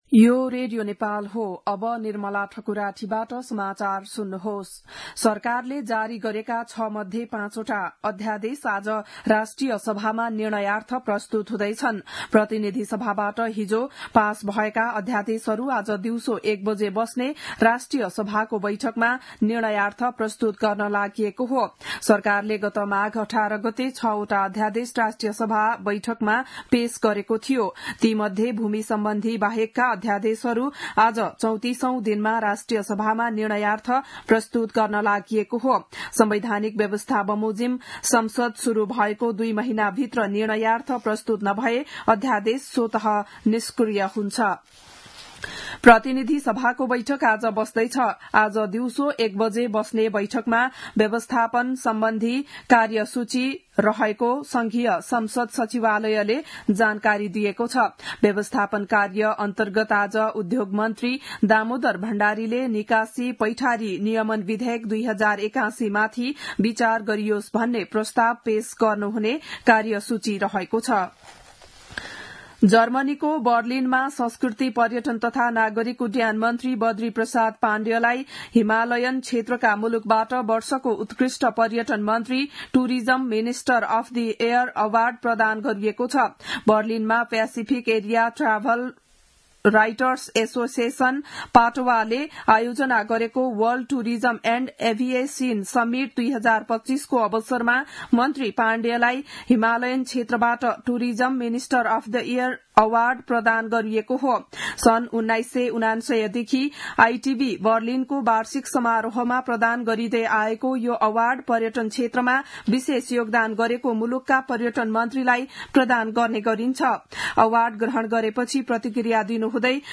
बिहान ११ बजेको नेपाली समाचार : २३ फागुन , २०८१
11-am-nepali-news-.mp3